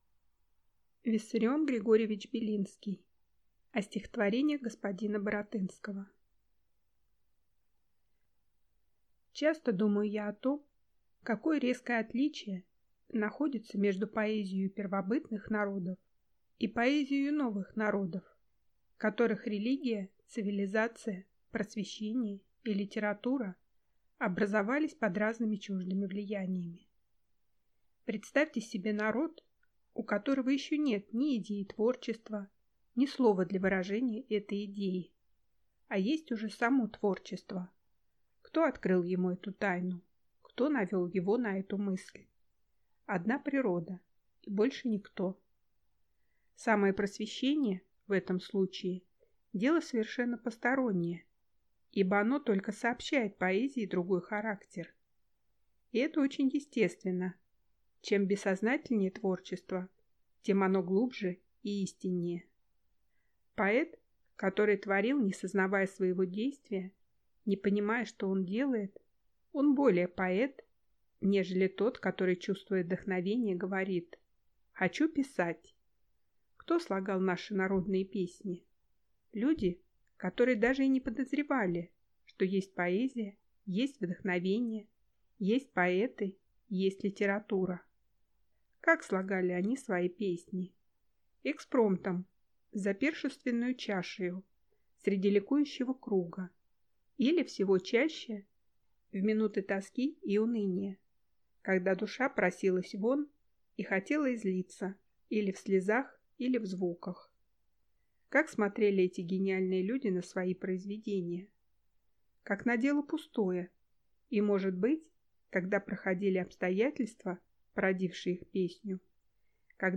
Аудиокнига О стихотворениях г. Баратынского | Библиотека аудиокниг